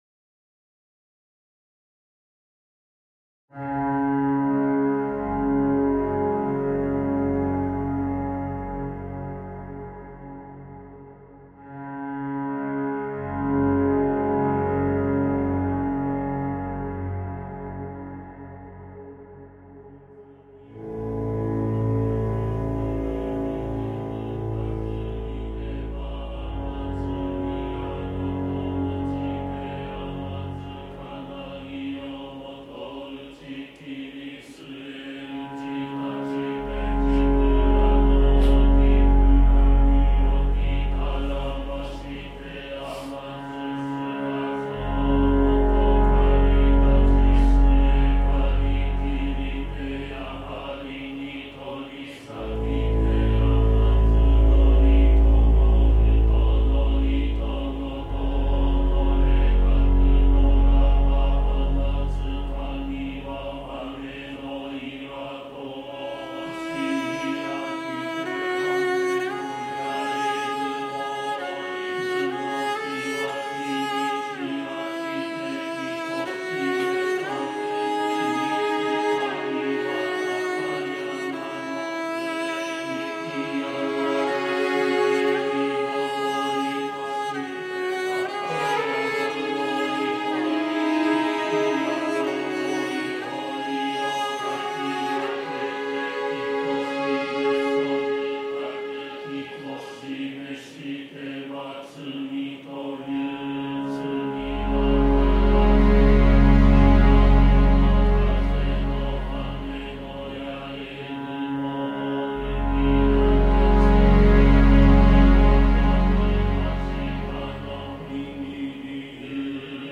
I was interested in this field recording, taken from Kasuga Taisha Shrine in Japan featuring monks chanting their morning prayer. I’ve aimed to create a musical space that allows their prayers to serve as a background and a constant, with distinct sections complementing and reflecting the ongoing prayer and the approaching dawn. Monks chanting in Nara reimagined